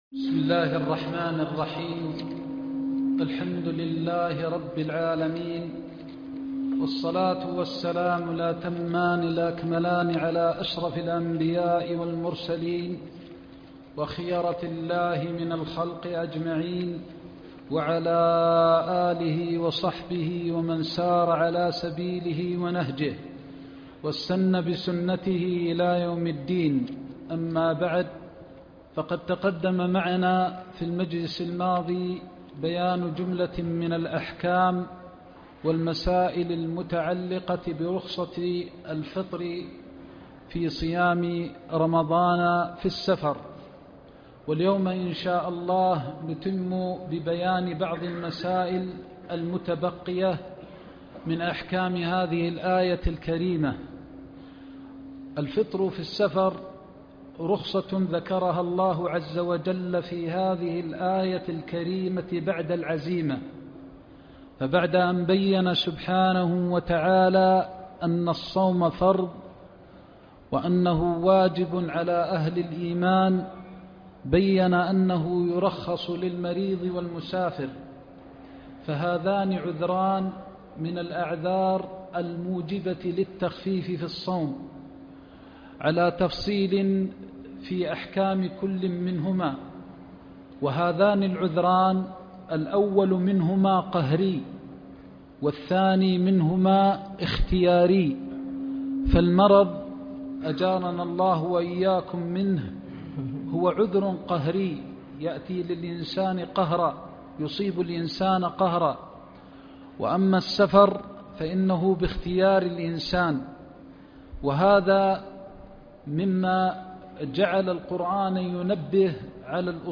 تفسير آيات الصيام 6-9-1443 هـ (درس الطائف في آيات الأحكام